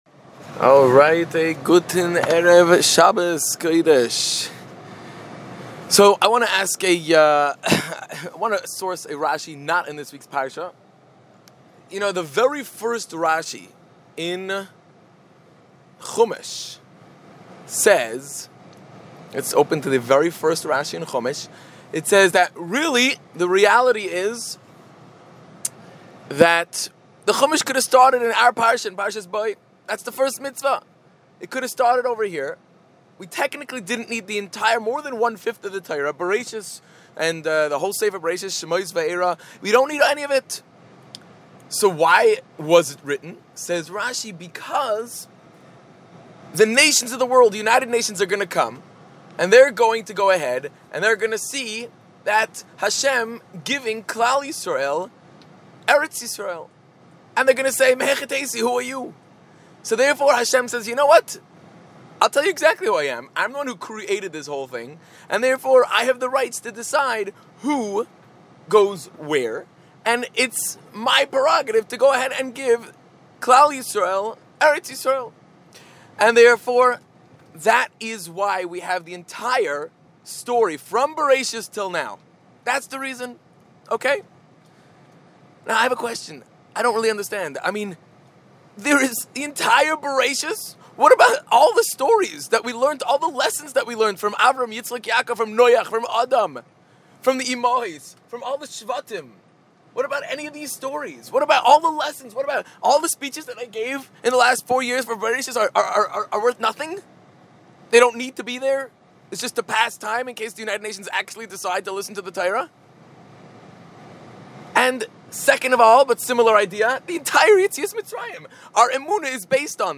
Shabbos speech Parshas Bo 5780